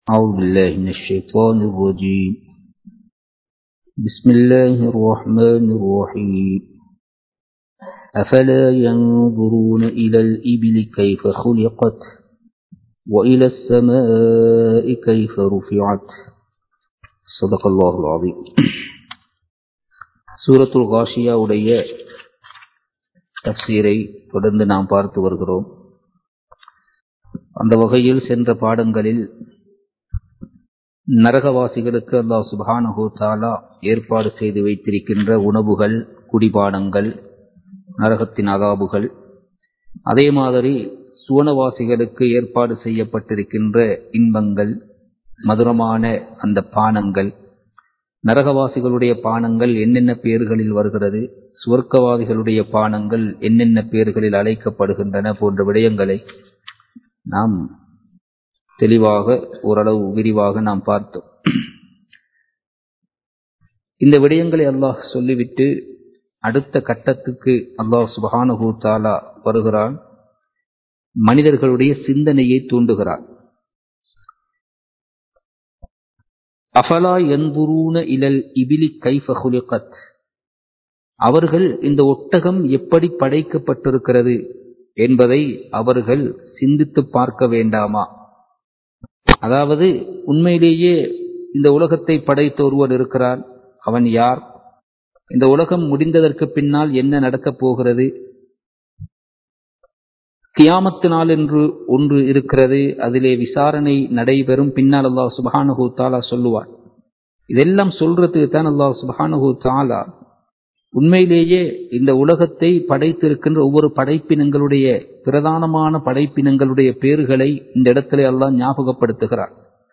தப்ஸீர் வகுப்பு 58 | Audio Bayans | All Ceylon Muslim Youth Community | Addalaichenai
Kandy, Kattukela Jumua Masjith